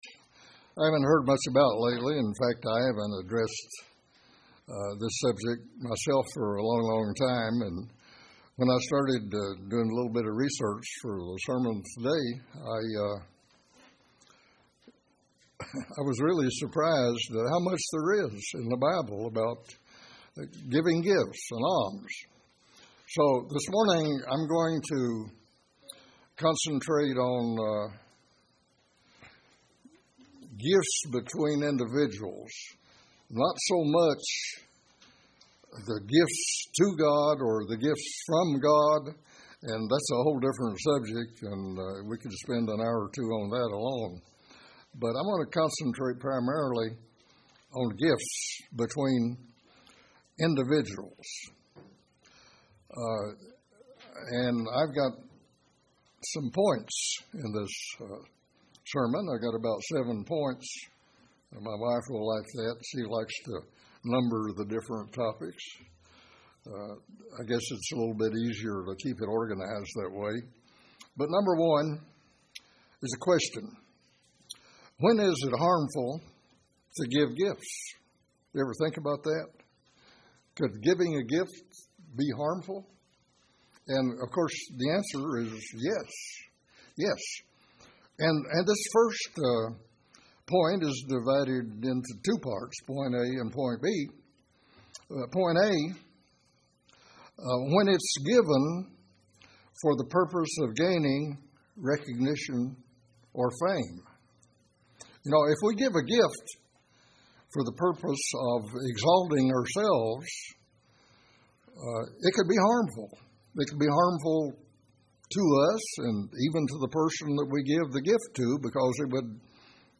Sometimes, do gifts hurt rather than heal or build up? In this sermon the truth is revealed....